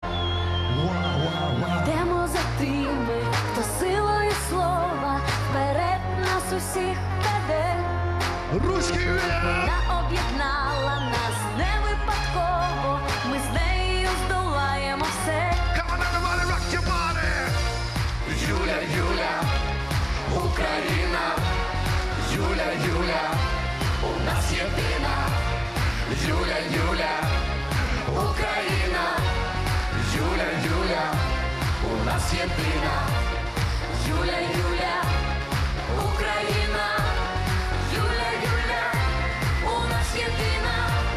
Агитационная песня